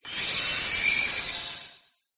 frosmoth_ambient.ogg